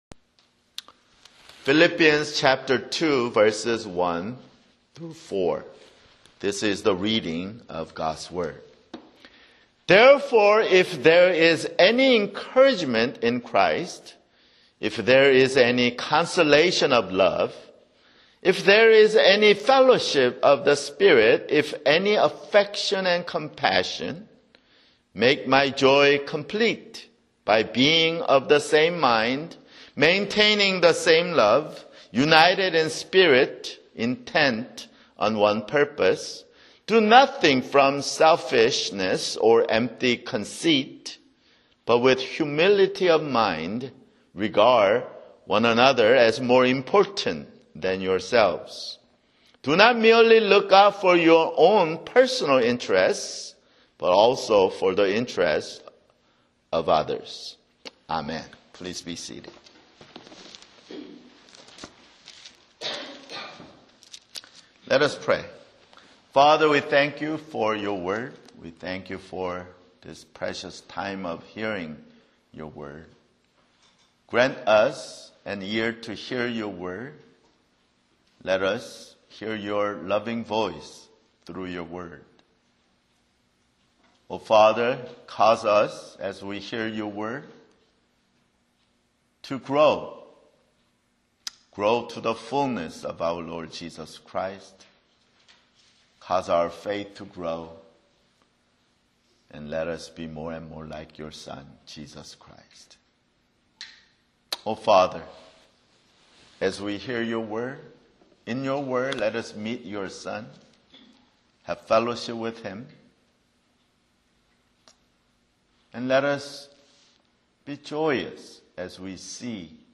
[Sermon] Philippians (22)